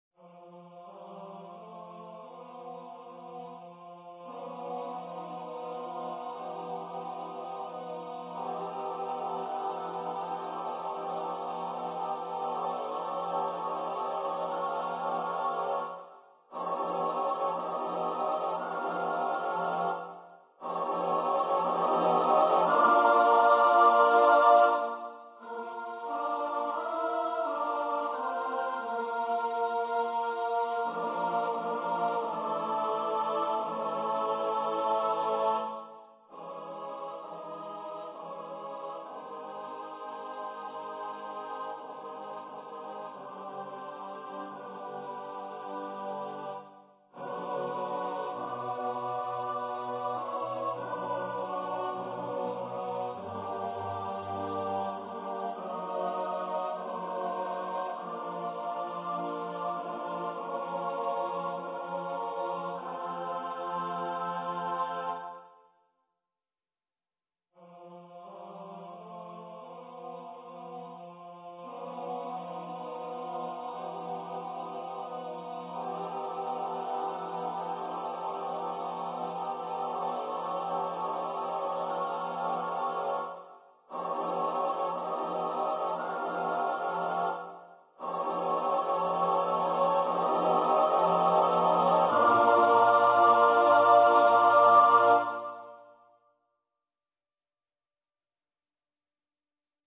for unaccompanied mixed voice choir